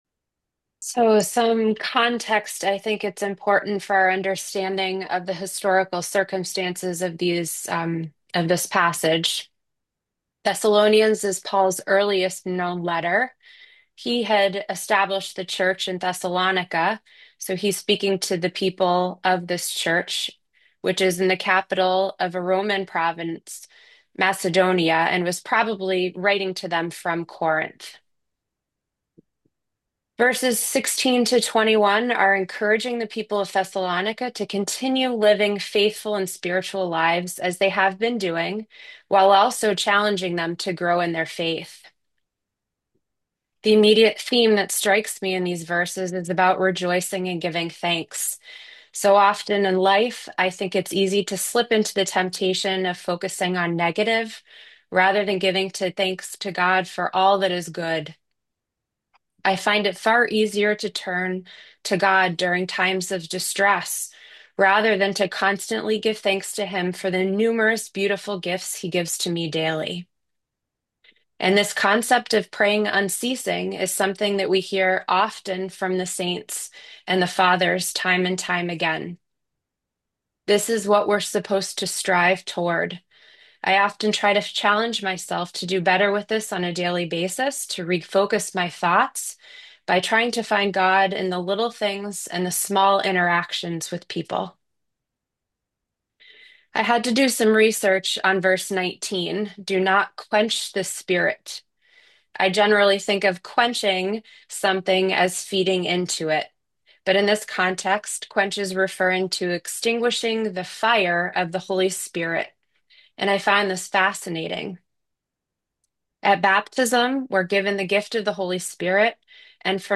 New Year’s Thanksgiving Prayer Service & Fellowship Zoom Event Reflections